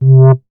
MoogSubVoc 007.WAV